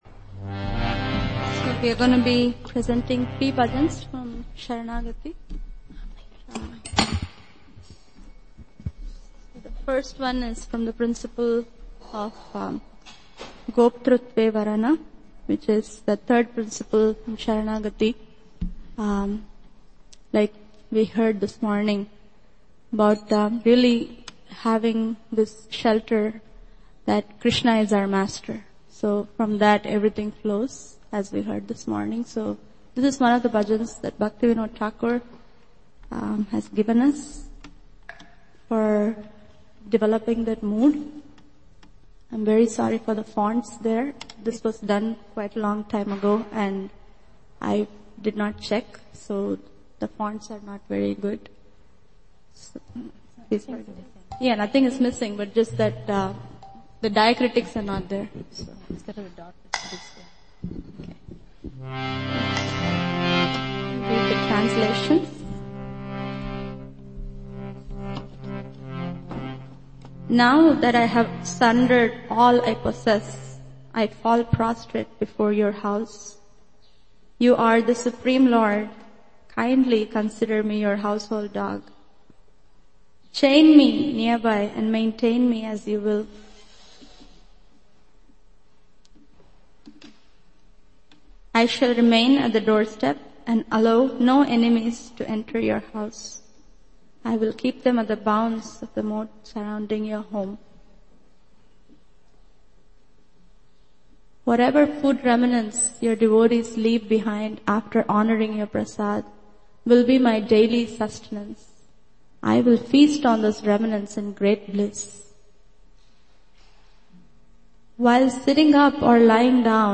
Saranagati songs